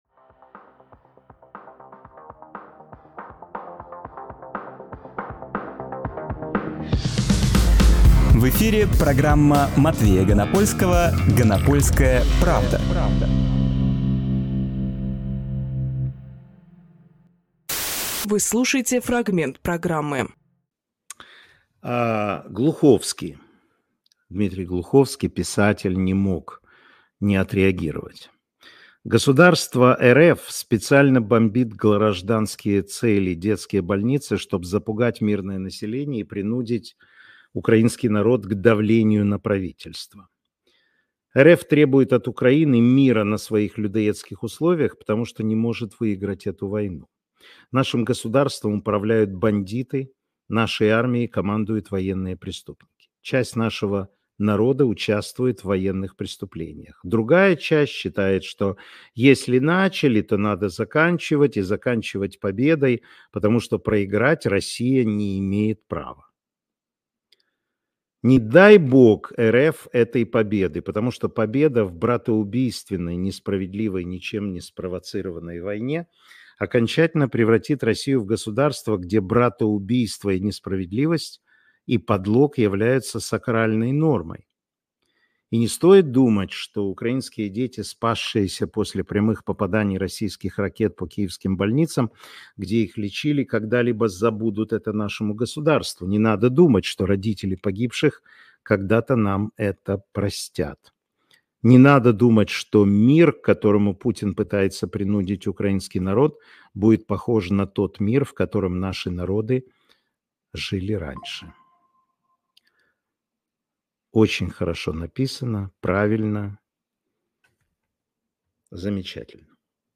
Фрагмент эфира от 10.07.24